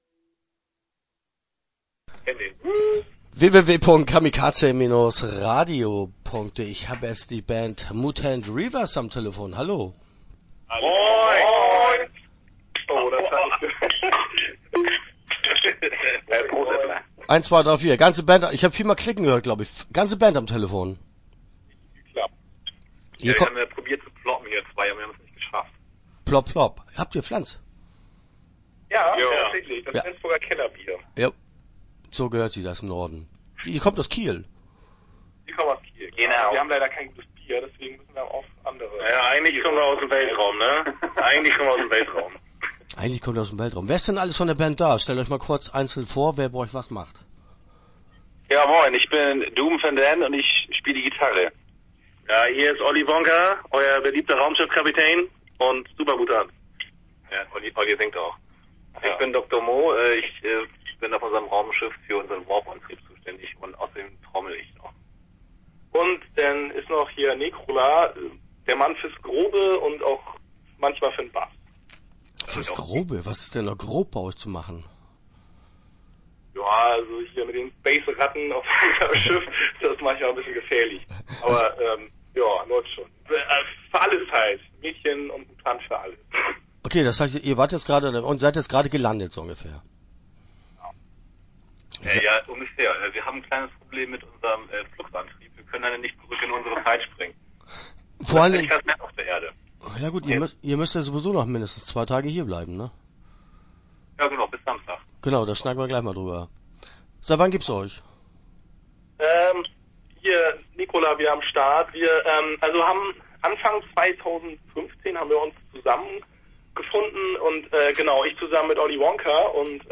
Mutant Reavers - Interview Teil 1 (10:22)